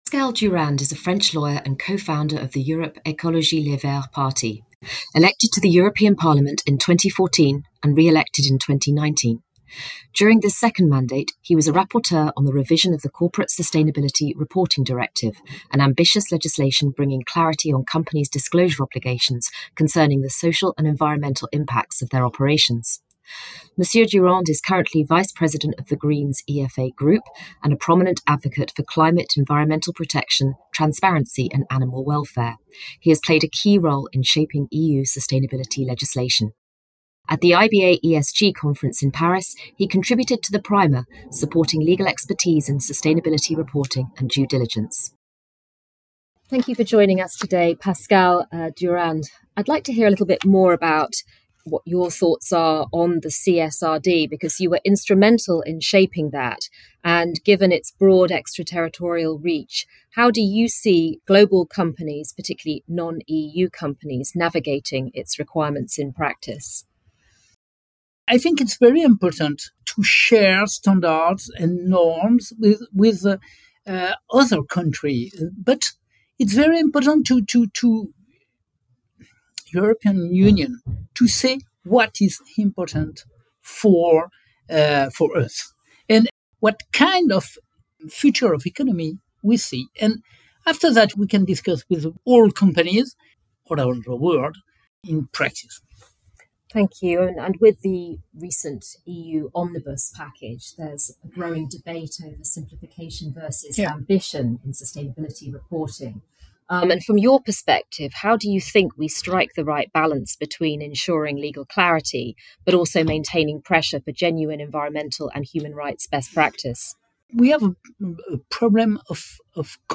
ESG Conference 2025: a conversation with Pascal Durand